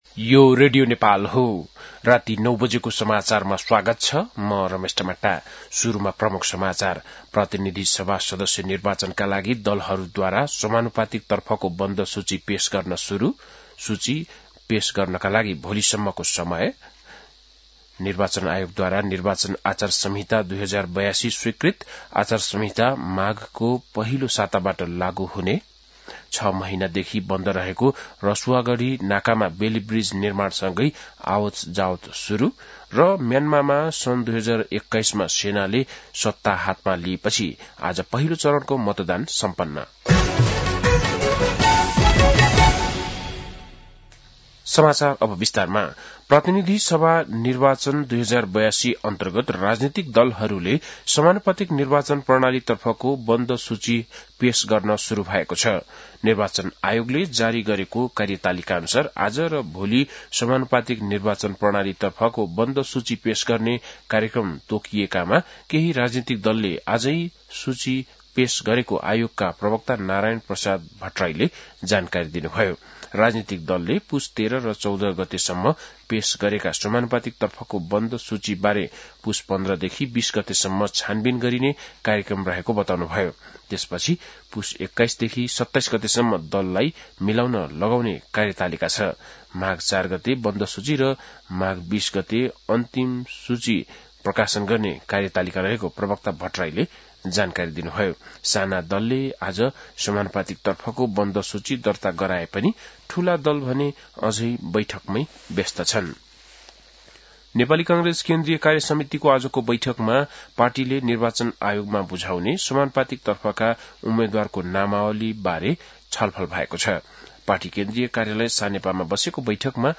बेलुकी ९ बजेको नेपाली समाचार : १३ पुष , २०८२
9-pm-nepali-news-1-2.mp3